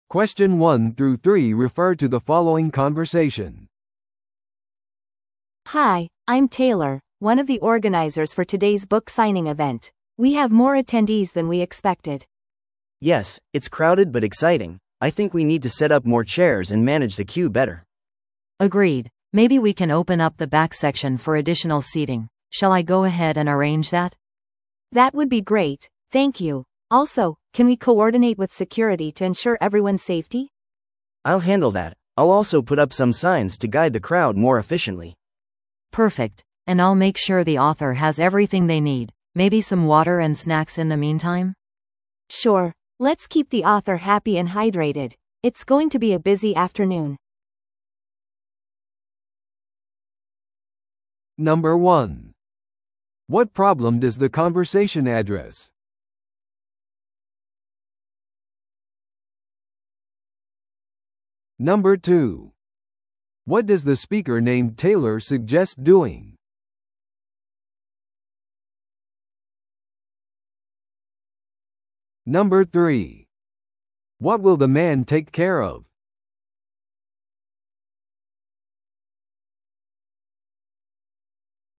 No.1. What problem does the conversation address?